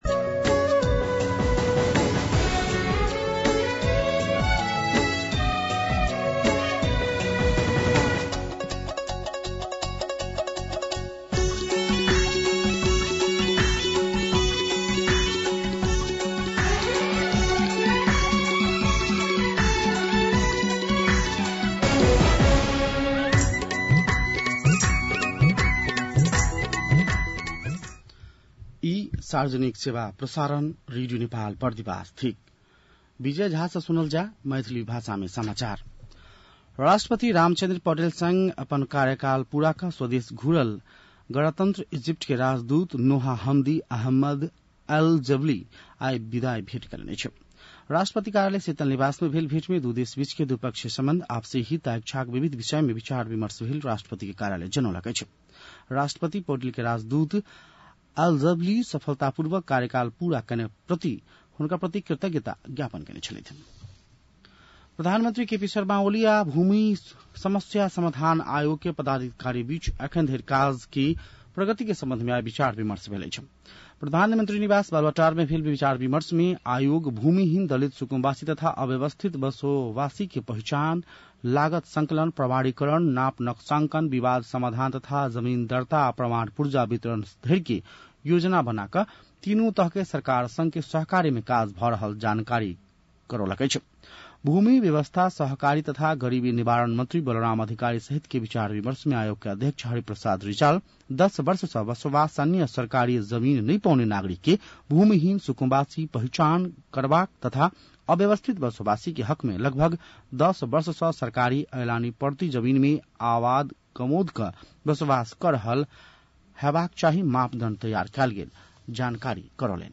मैथिली भाषामा समाचार : २५ जेठ , २०८२
6.-pm-maithali-news-.mp3